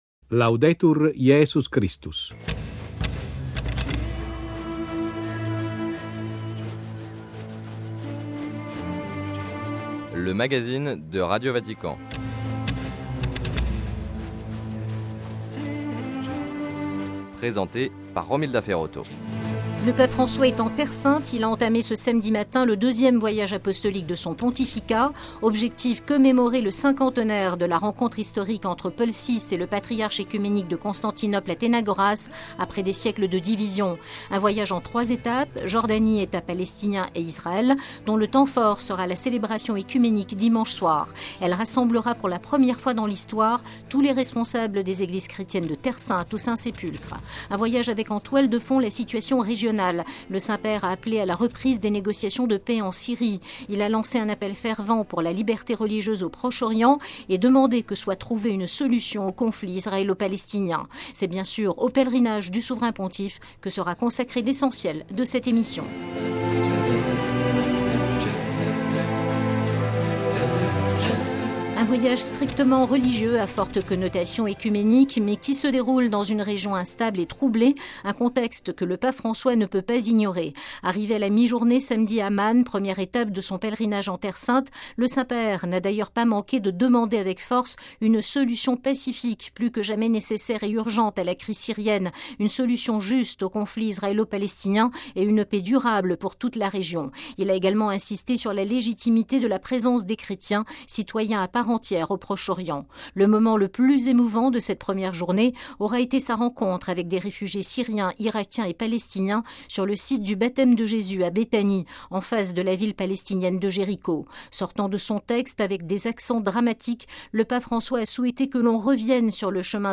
Sommaire : - Compte-rendu de la première journée du pèlerinage du Pape François en Terre Sainte. - Entretiens